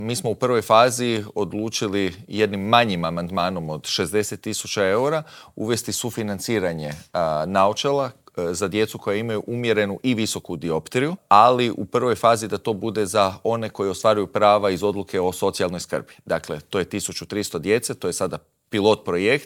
O ovim i drugim pitanjima smo u Intervjuu tjedna Media servisa razgovarali s predsjednikom zagrebačke Gradske skupštine, Matejem Mišićem.